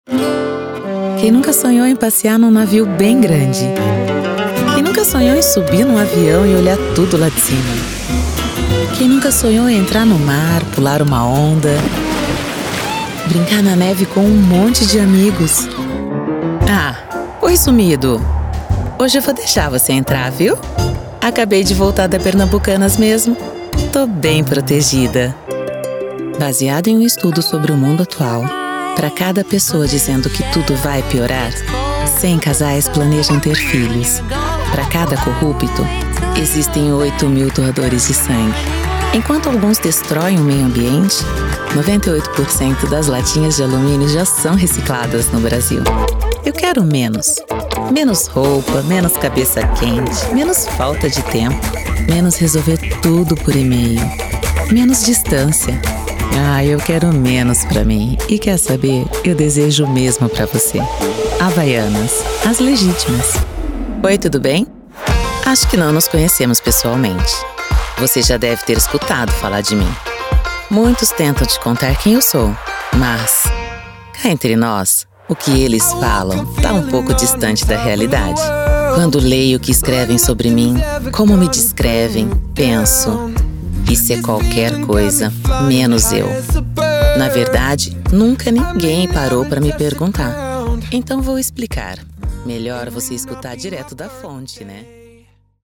Conversacional
Amigáveis
Exato